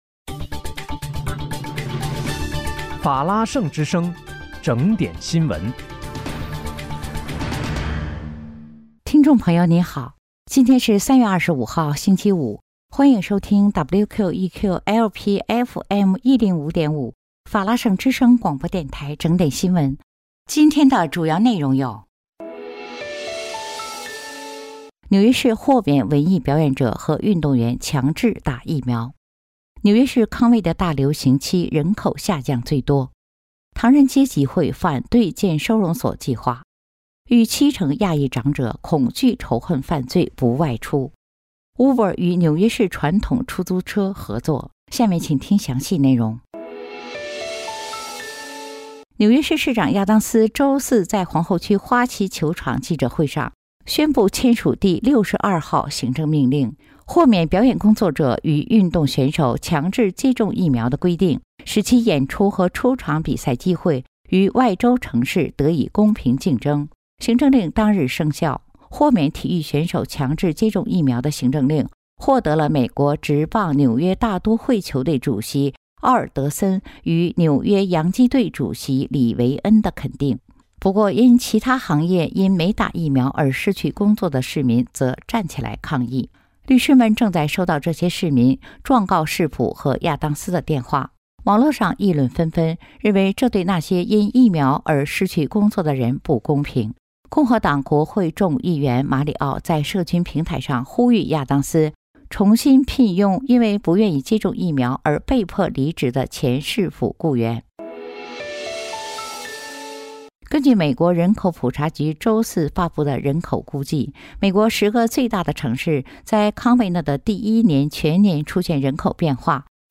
3月25日（星期五）纽约整点新闻
今天是3月25号，星期五，欢迎收听WQEQ-LP FM105.5法拉盛之声广播电台整点新闻。